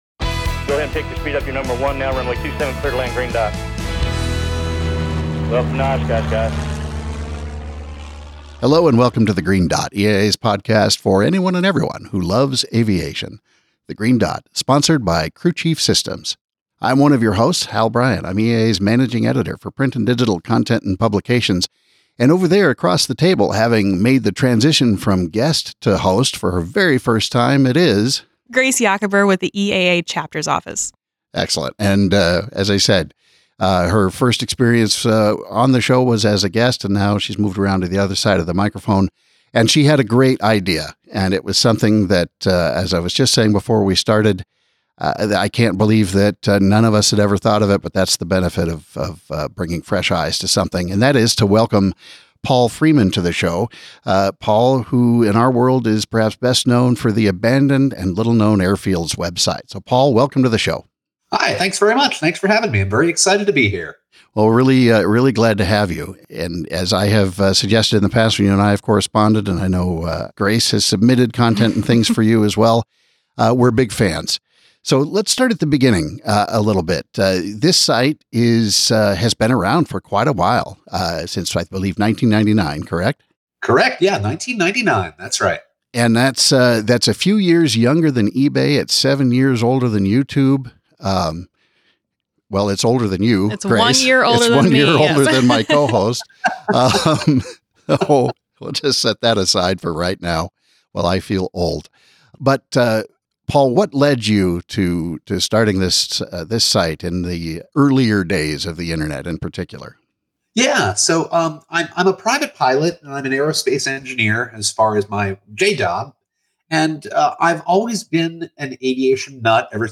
EAA's The Green Dot - An Aviation Podcast